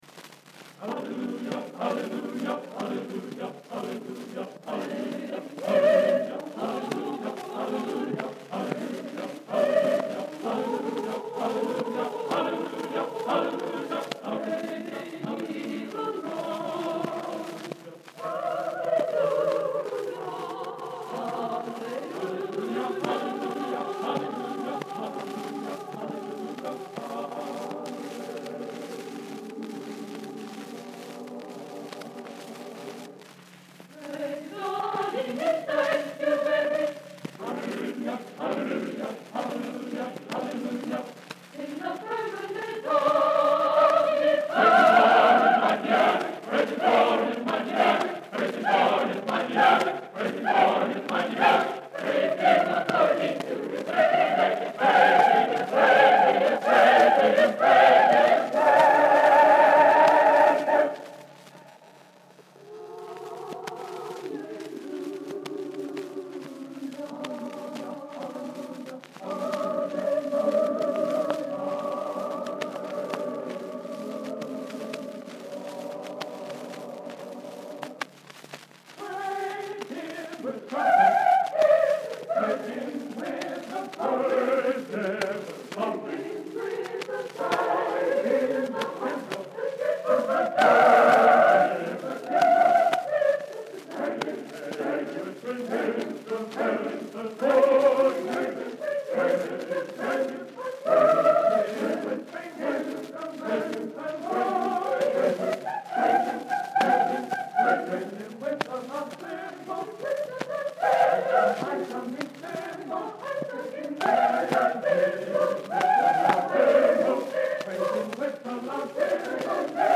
Bethany Peniel College (BPC) A Cappella Choir recordings from 1953-1954.